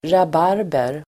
Ladda ner uttalet
rabarber substantiv, rhubarb Uttal: [rab'ar:ber]